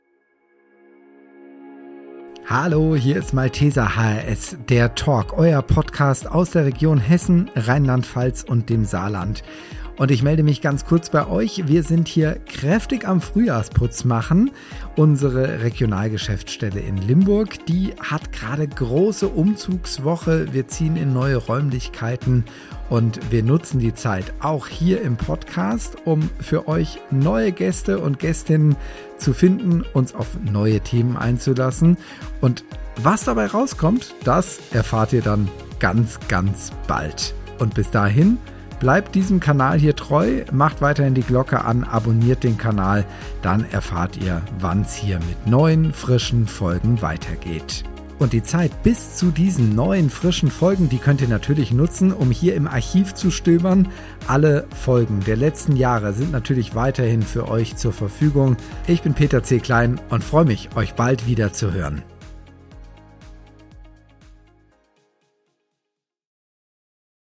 Einmal feucht durchwischen bitte – und die neuen Schreibtische